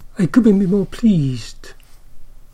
|aɪ ˈkʊbm bi ˈmɔː ˈpliːzd|
I-couldnt-be-more-pleased-with-double-assimilation.mp3